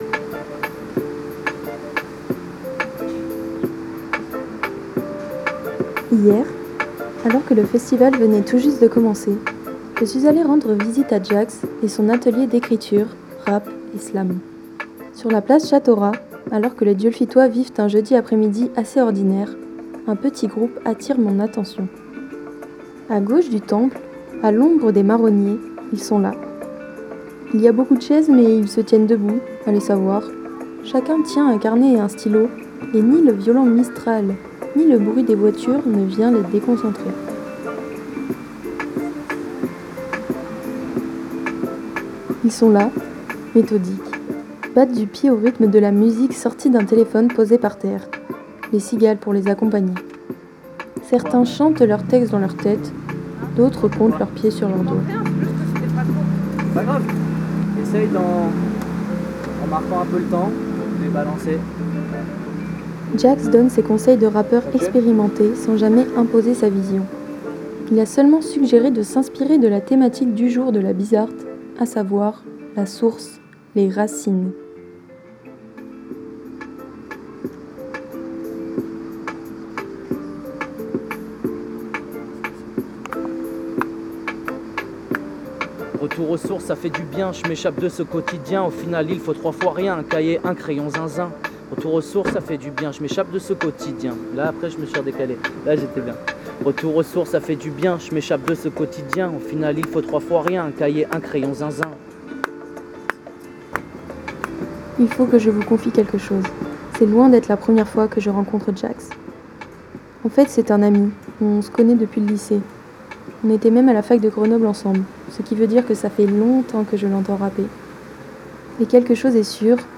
18 juillet 2022 12:14 | radio bizz'art, Radio bizz'art 2022, reportage
L’Oasis Bizz’Art, c’est non seulement une programmation musicale tournée sur le monde, mais c’est aussi toute une série de stages et ateliers ouverts à tous les festivaliers et festivalières.